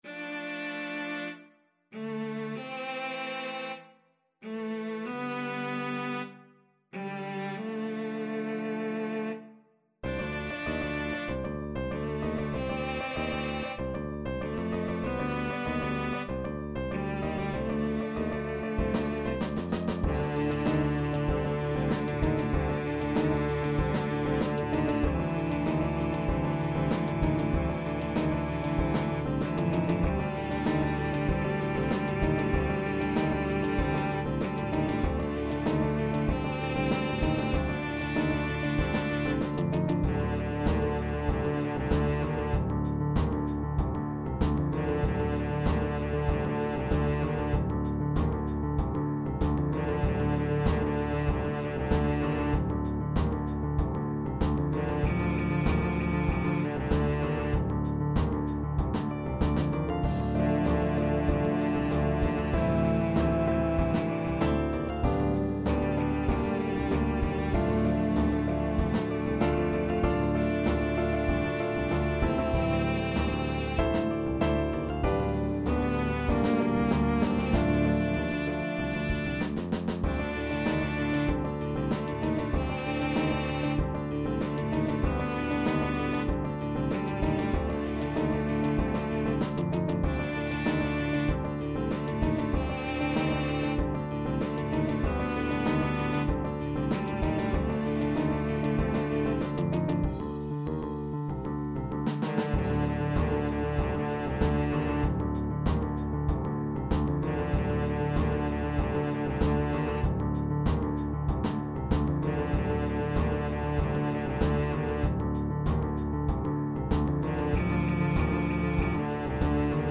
Engel (tenor)